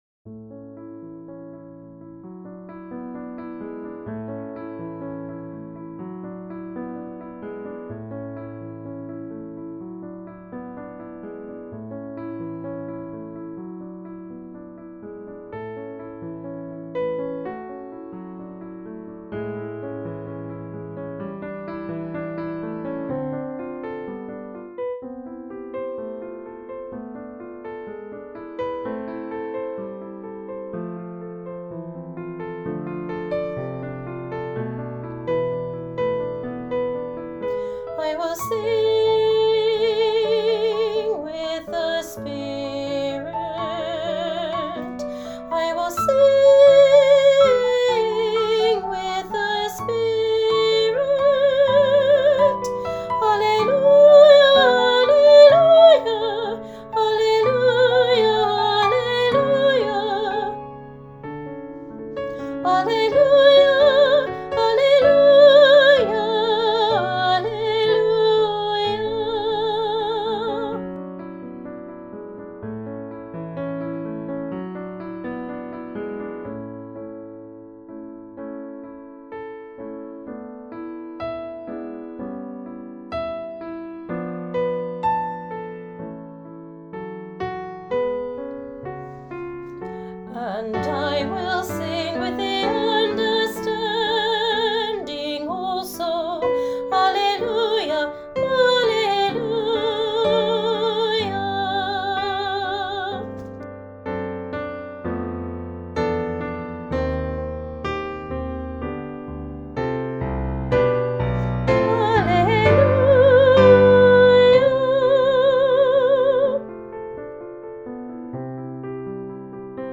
Junior-Choir-I-Will-Sing-with-the-Spirit-part-2.mp3